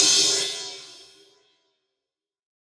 DnBCymbalA-04.wav